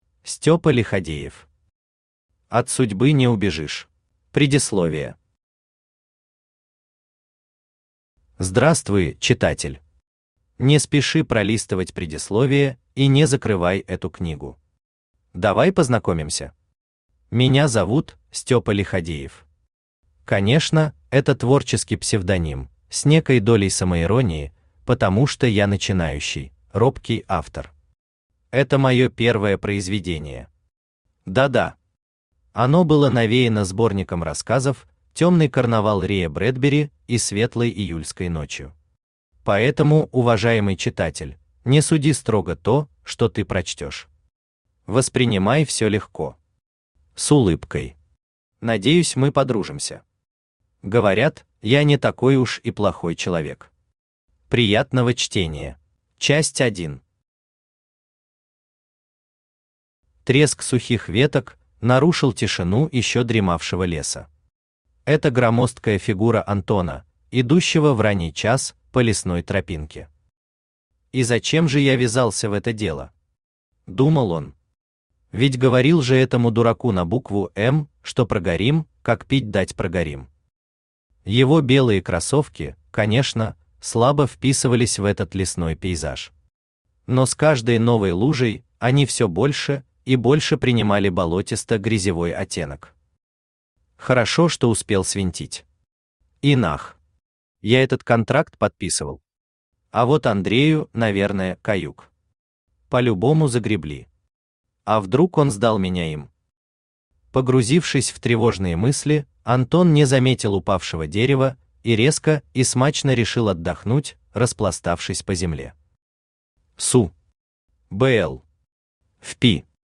Читает: Авточтец ЛитРес
Аудиокнига «От судьбы не убежишь».